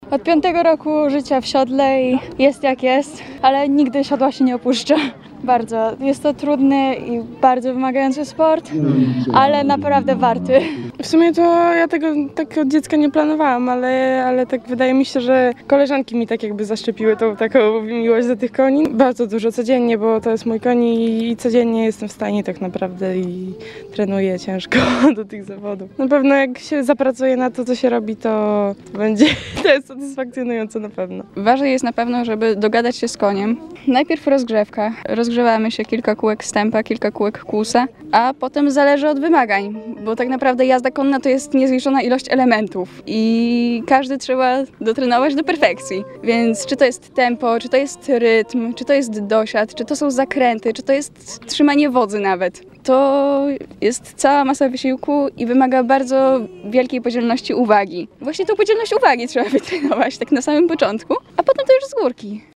Jak zapewniają uczestnicy konkursu, przygotowania do zawodów wymagają ogromu czasu, poświęceń i treningów, ale dają ogromną satysfakcję.
uczestnicy.mp3